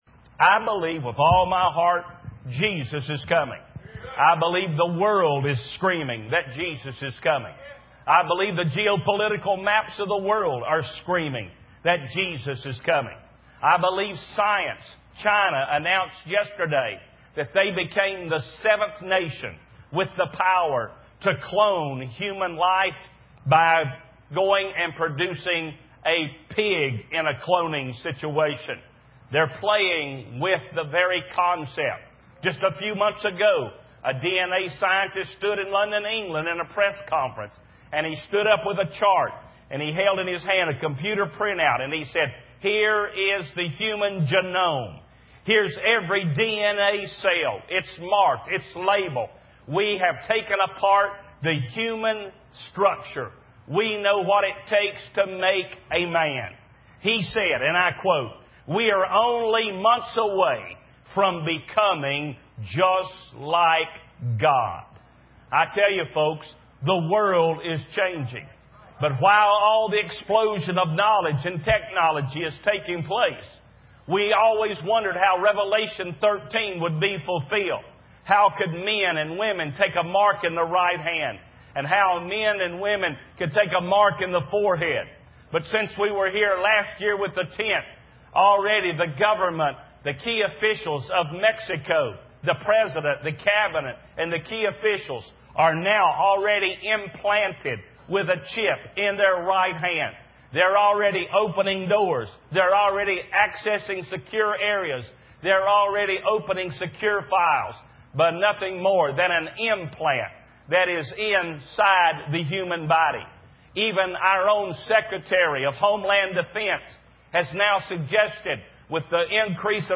In this sermon, the preacher discusses the promise of God to wipe away all tears from the eyes of believers. He emphasizes that one day, all pain, sorrow, and death will be eliminated, and believers will dwell with God. The preacher also addresses current events such as fear, terrorism, and the desire for bio-identification markers, highlighting the changing world and the power of energy markets.